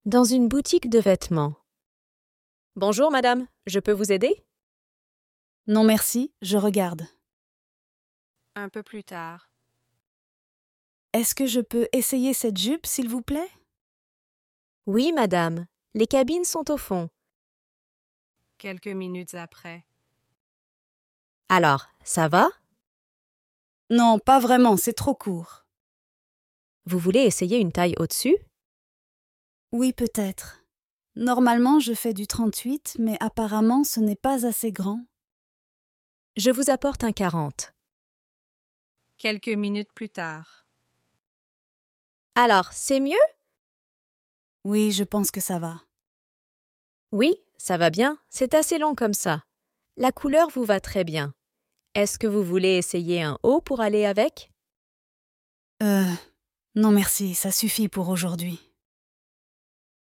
Dialogue en français dans une boutique de vêtements PDF